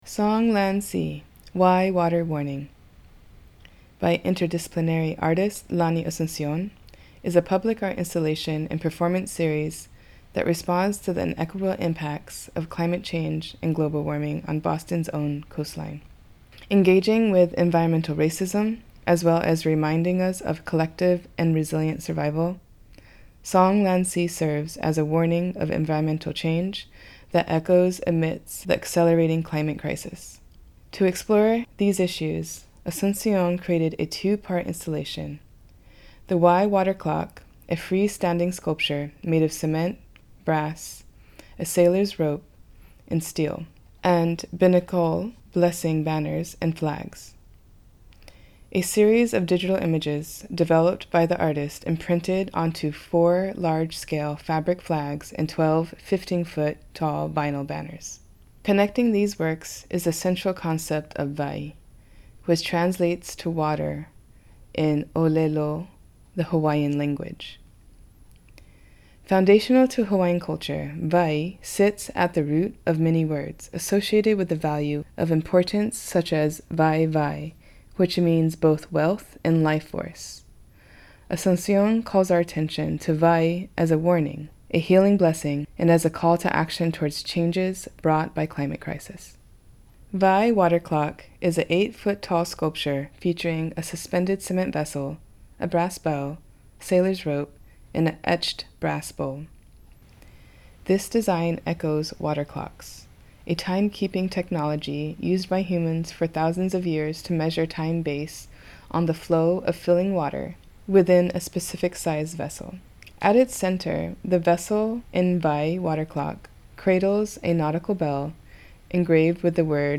SONGLANDSEA-Audio-Description.mp3